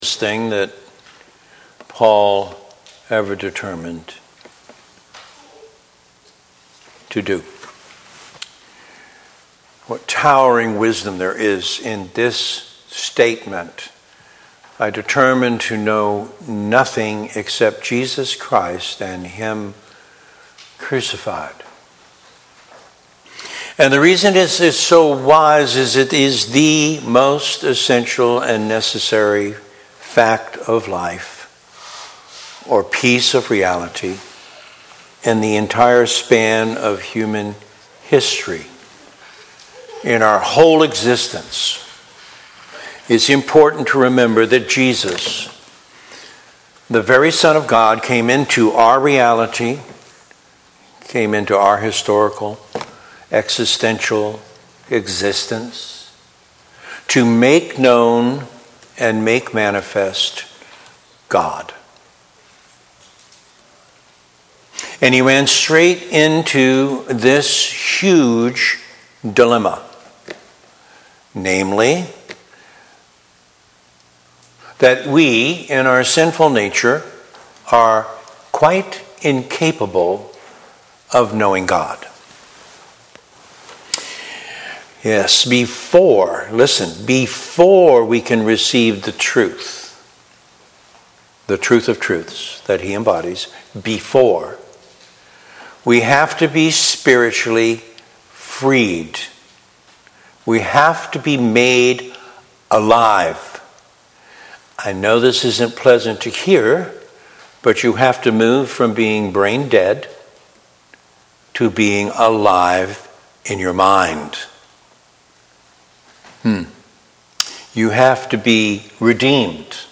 Sermons | East Winthrop Baptist Church | Page 8